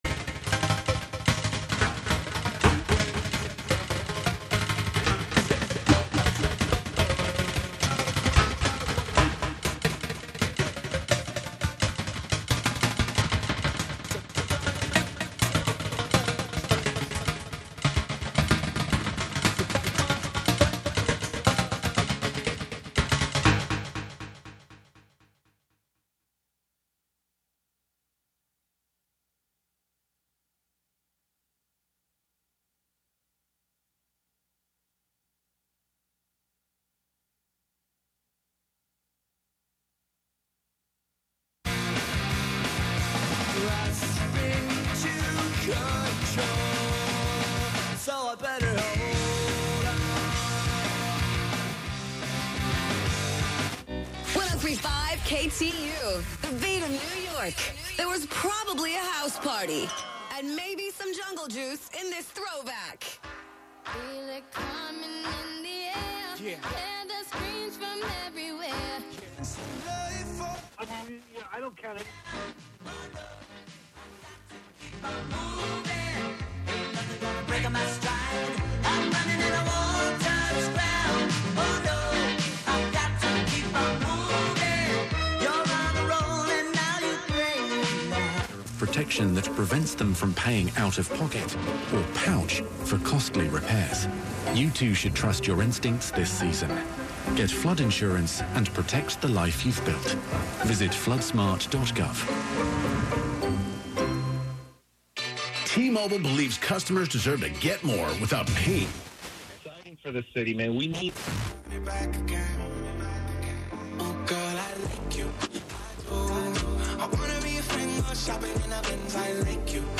11am Live from Brooklyn, New York
turns the very bits and bytes of commercial culture into the driving backbeat to our dance of independence. "Radio Wonderland" abstracts live FM radio with laptop, electrified shoes hit with sticks, and a computer-hacked steering wheel (from a Buick 6).